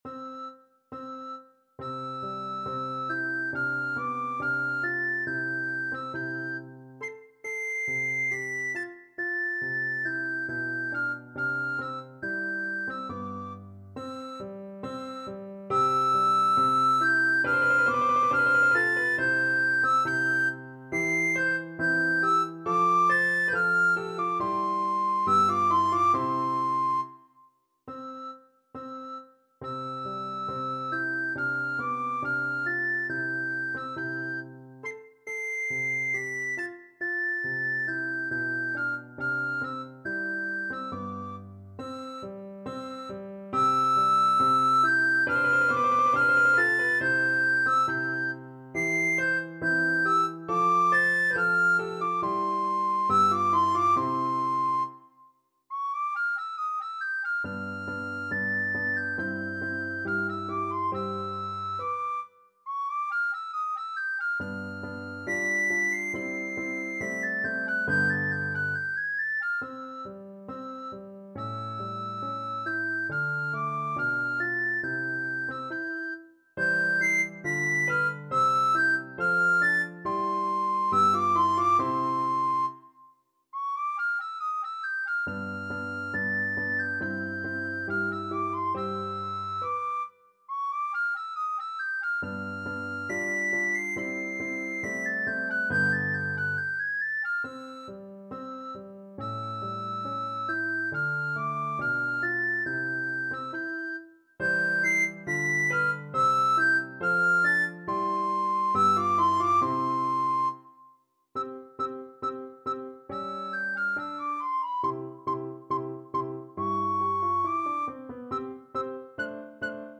Free Sheet music for Soprano (Descant) Recorder
RecorderPiano
4/4 (View more 4/4 Music)
Andante =69
C major (Sounding Pitch) (View more C major Music for Recorder )
Classical (View more Classical Recorder Music)